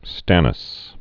(stănəs)